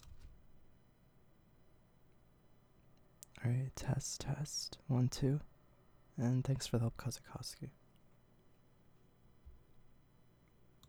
Blue Yeti Problem? Hissing Sound
No gain, 50 volume via Windows Mixer.
The whine is comparable in amplitude to the “room tone”.
Your voice sounds like you are speaking quite softly however.